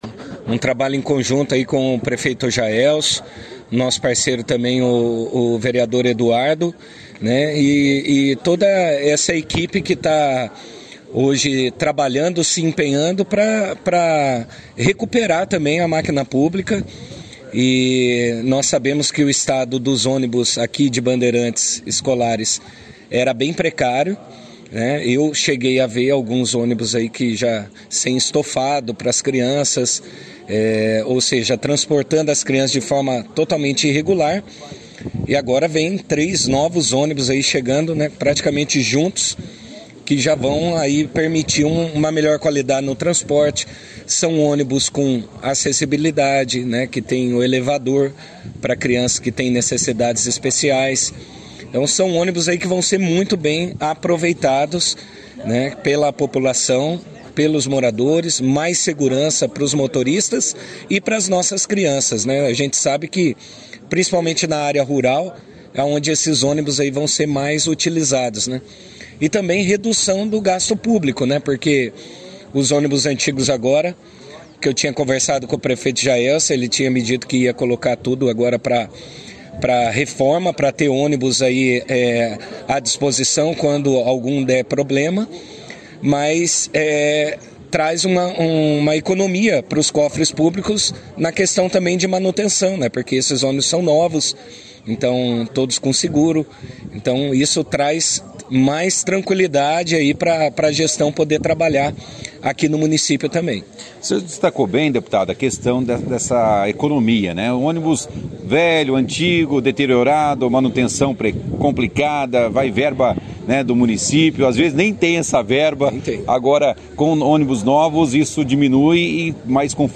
Na manhã da última segunda-feira, 27/06, a prefeitura de Bandeirantes recebeu oficialmente 03 ônibus escolar, num total de R$ 953.700,00 conseguido através de verba indicada pelo Deputado Federal, Diego Garcia . O Deputado participou da 1ª edição do Jornal Operação Cidade desta terça-feira, 28/06, falando sobre a entrega.